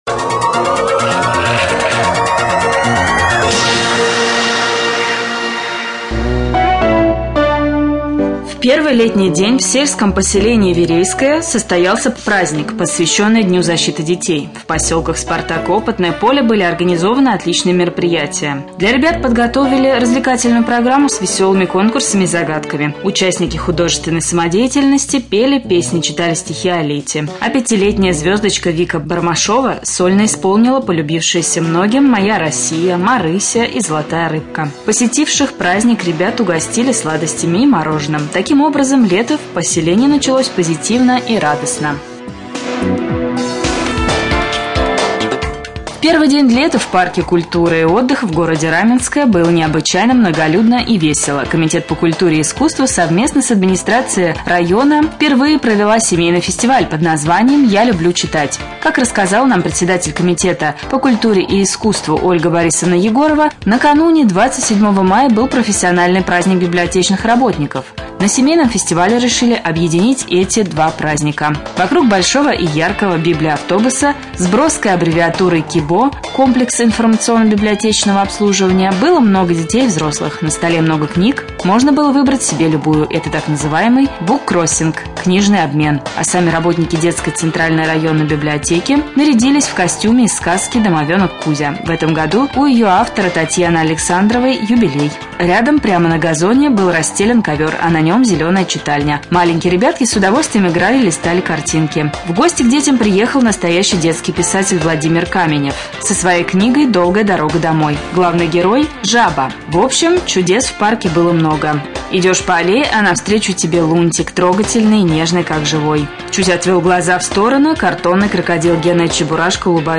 04.06.2014Г. в эфире раменского радио
1.Новости.mp3